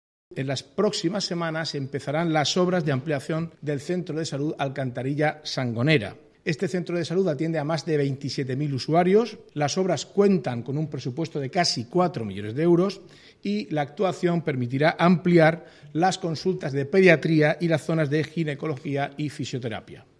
Declaraciones del consejero de Presidencia, Portavocía y Acción Exterior, Marcos Ortuño, sobre la ampliación del centro de salud de Alcantarilla-Sangonera, y sobre la incorporación del municipio al Área Metropolitana de Murcia. Marcos Ortuño ha comparecido tras la reunión mantenida en San Esteban entre el presidente de la Comunidad, Fernando López Miras, y el alcalde de Alcantarilla, Joaquín Buendía.